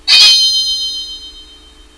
開いたときに（噂の）
「シャキ〜ン」音を鳴らすことが出来たりもするし、ね。
shakiiin.wav